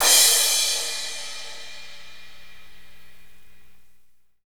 Index of /90_sSampleCDs/Northstar - Drumscapes Roland/CYM_Cymbals 3/CYM_P_C Cyms x